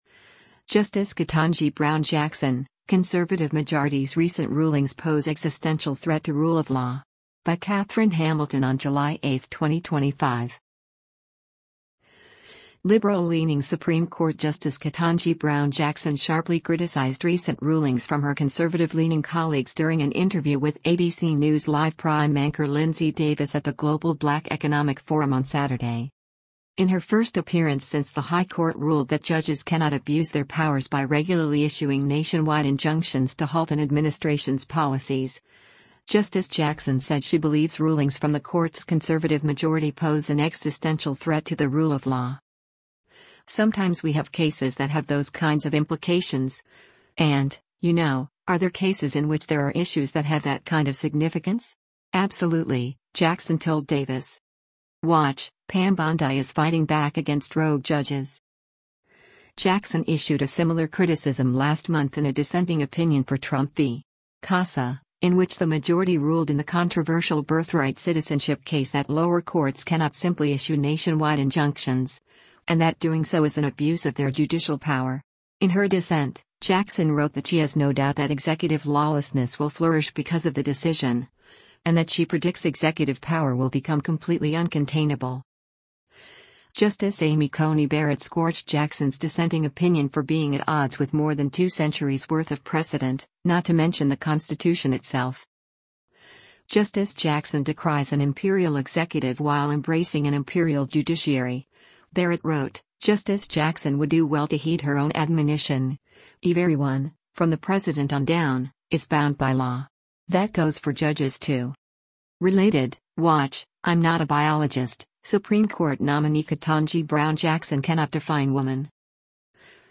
Liberal-leaning Supreme Court Justice Ketanji Brown Jackson sharply criticized recent rulings from her conservative-leaning colleagues during an interview with ABC News Live Prime anchor Linsey Davis at the Global Black Economic Forum on Saturday.
Jackson did not directly address the case or criticisms during the interview, which took place at the ESSENCE Festival of Culture in New Orleans, Louisiana, although she said she has a right to express her views.